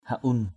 /ha-un/ (đg.) lót trên đầu = mettre un bourrelet sous la charge que l’on porte sur la tête. siém ha-un _s`#’ huN khăn lót để đội đồ vật lên đầu...